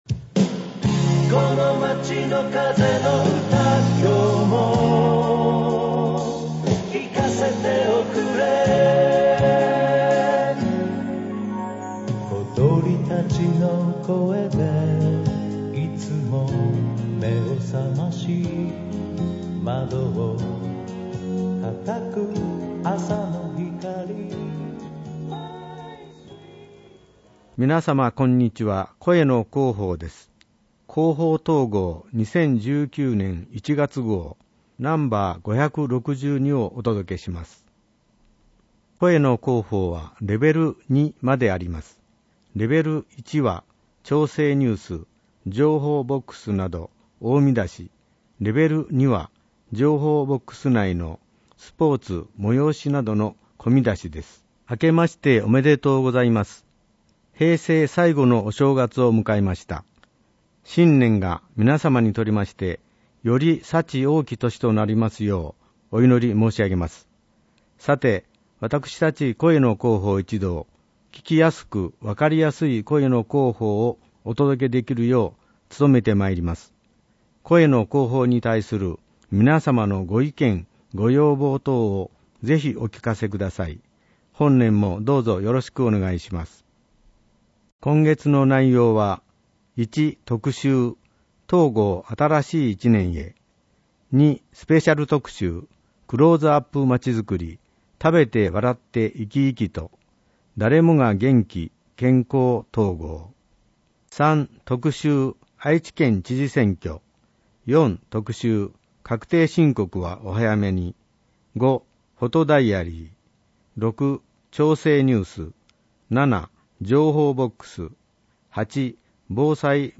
広報とうごう音訳版（2019年1月号）